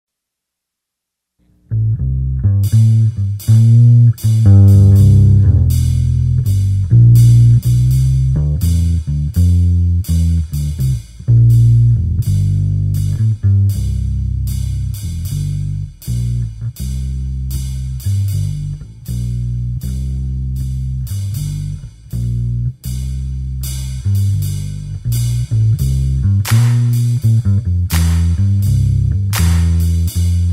Instrumental Tracks.
▪ The full instrumental track